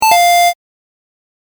正解の音・ピンポーンだけを集めた、フリー素材です！
▶正解７８（ファミコン風のピンポン。中音ver）【DL】
correct078.mp3